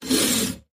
in_bowsaw_stroke_04_hpx
Bow saw squeaks while sawing hardwood. Tools, Hand Wood, Sawing Saw, Squeak